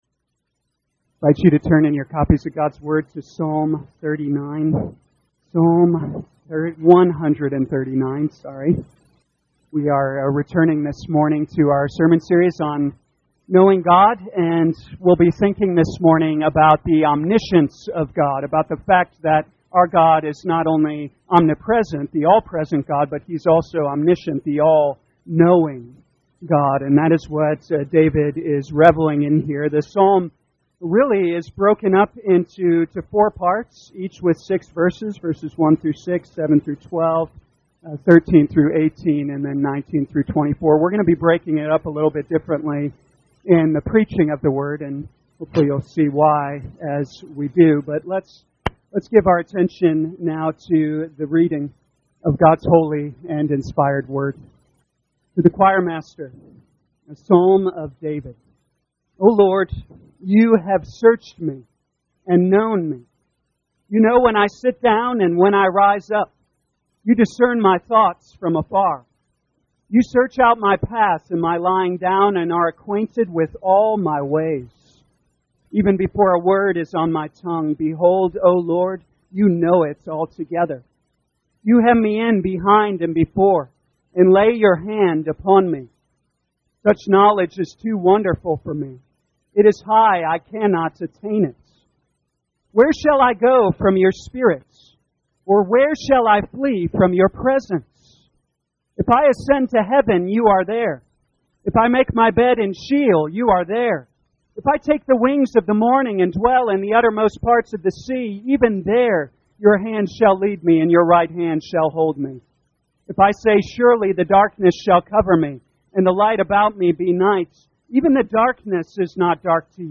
2023 Psalms Knowing God Morning Service Download